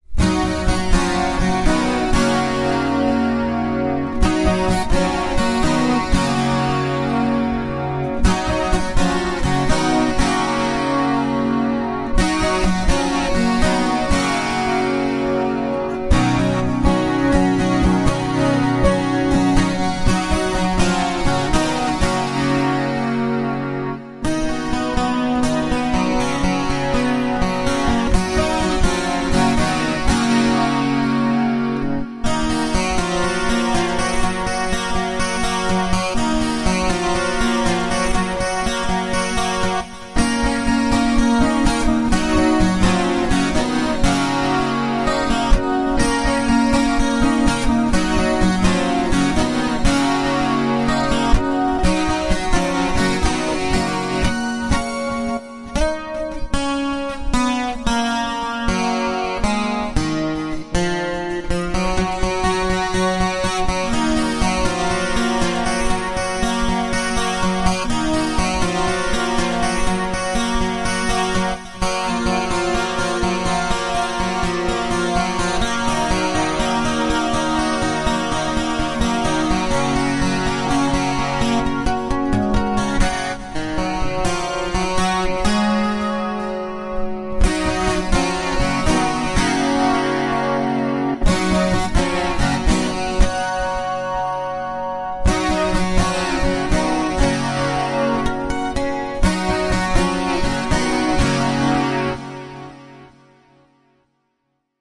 描述：12弦原声吉他上带有和弦的短旋律。
标签： 12 声学 吉他
声道立体声